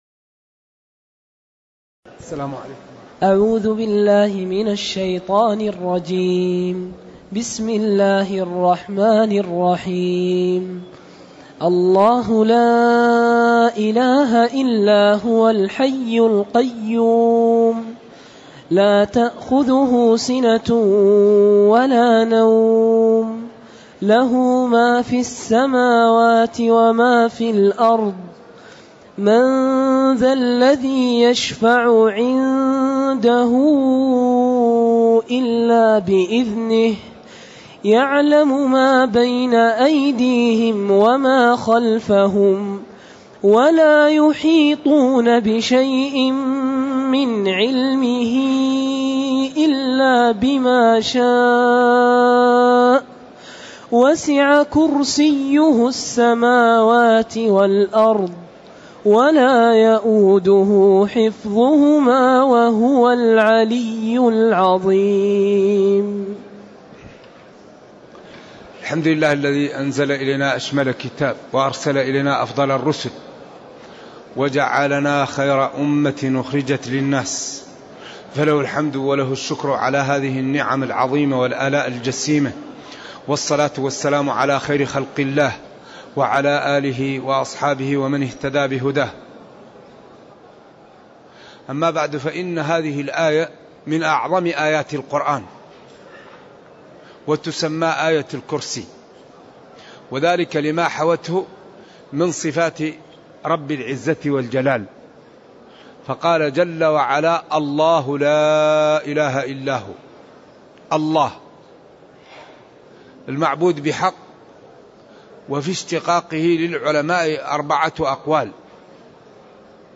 تاريخ النشر ١٩ ذو القعدة ١٤٢٨ هـ المكان: المسجد النبوي الشيخ